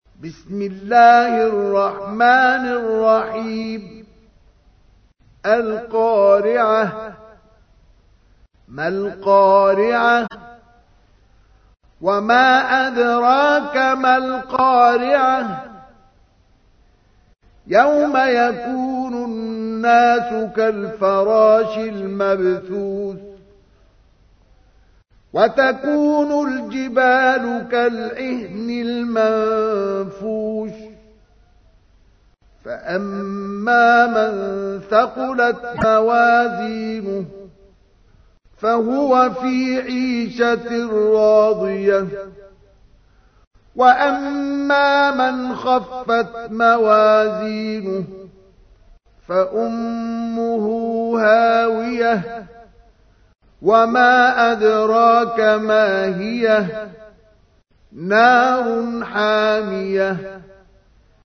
تحميل : 101. سورة القارعة / القارئ مصطفى اسماعيل / القرآن الكريم / موقع يا حسين